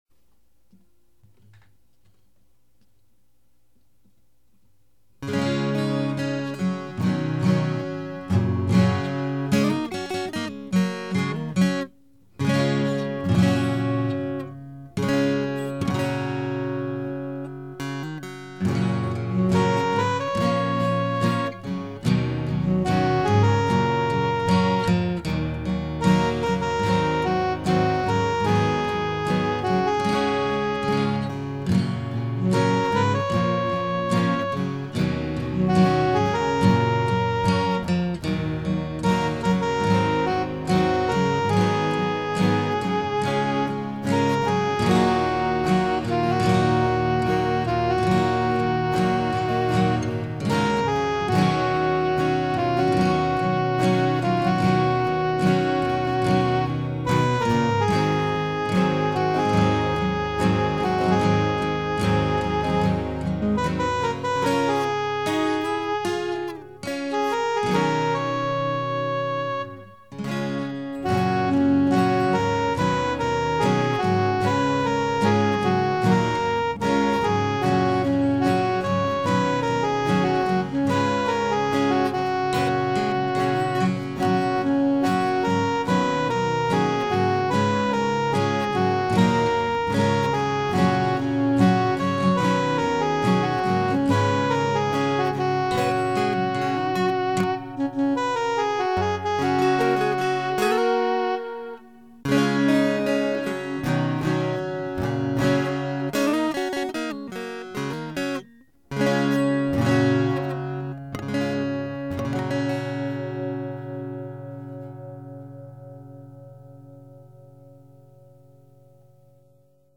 TR2 A-Giter
TR3 E-piano
ギター2本で弾いてみました。
カラオケっぽく主旋律をいれてみました